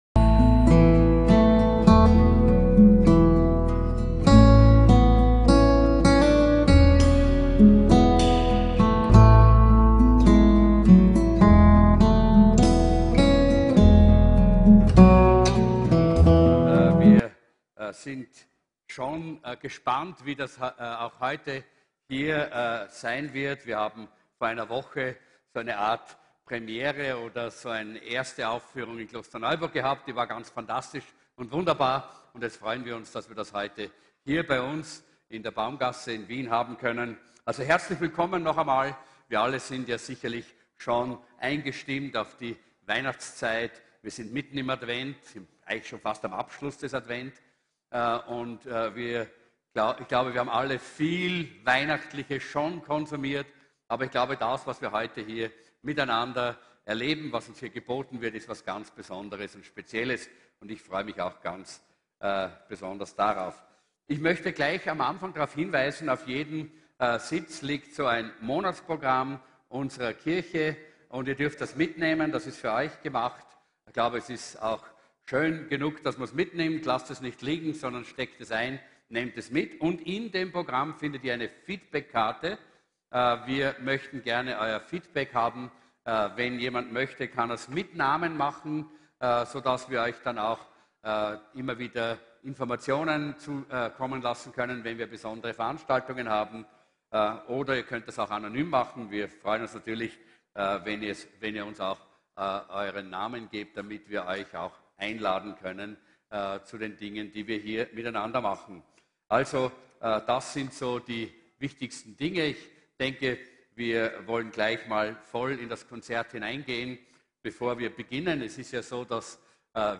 WEIHNACHTSKONZERT